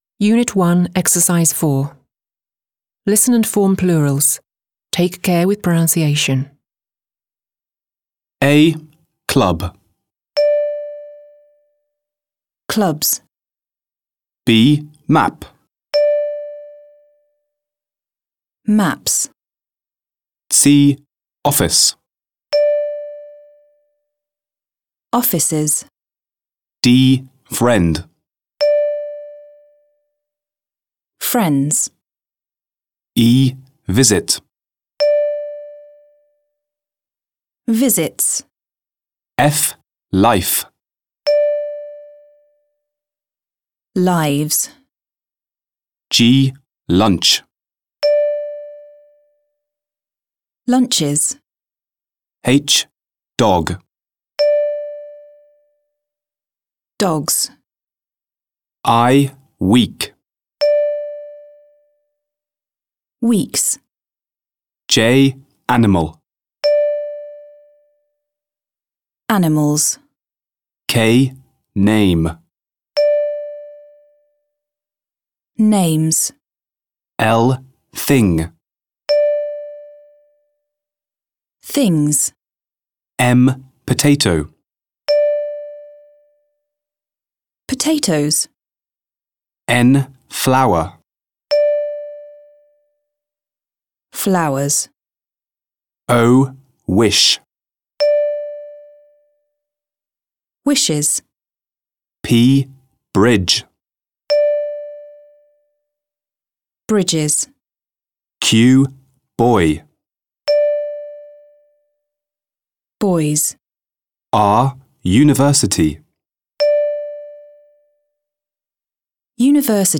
• Posluchové cvičenia sú nahrané rodenými hovoriacimi na CD vo formáte MP3.